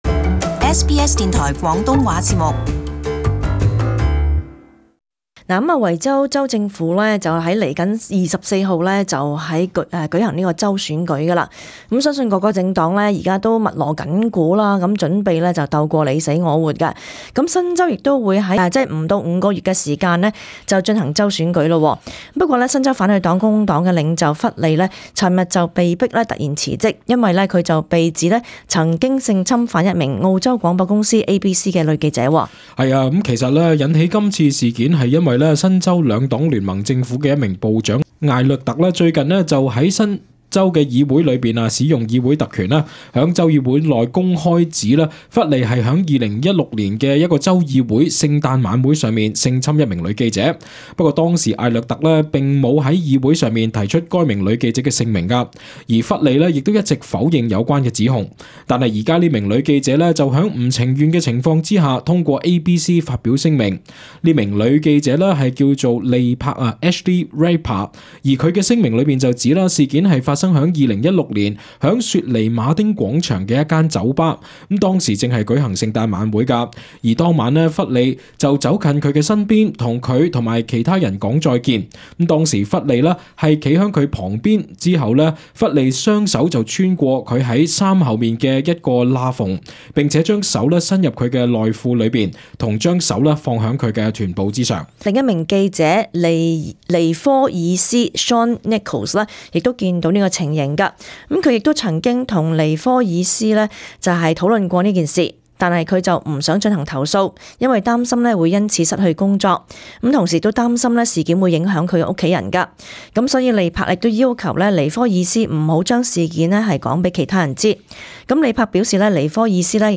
【時事報導】新州反對黨領袖弗利涉性醜聞辭職， 同時考慮採取法律行動。